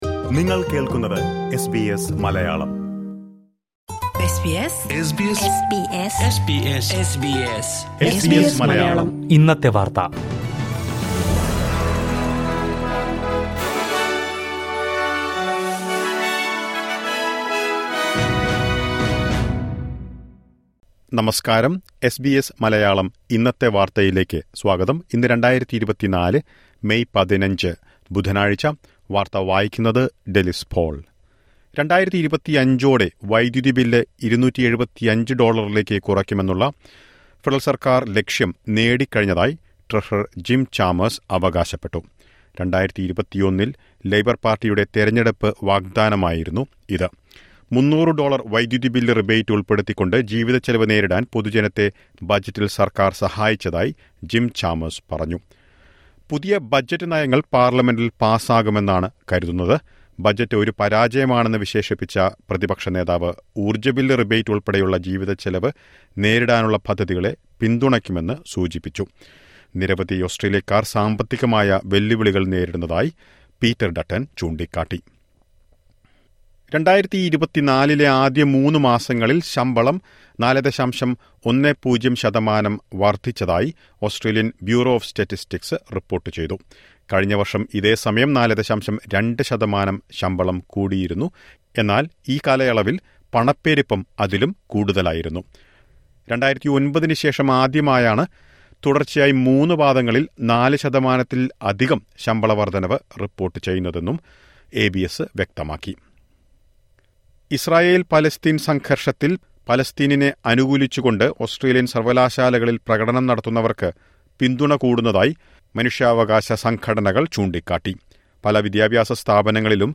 2024 മെയ് 15ലെ ഓസ്‌ട്രേലിയയിലെ ഏറ്റവും പ്രധാന വാര്‍ത്തകള്‍ കേള്‍ക്കാം...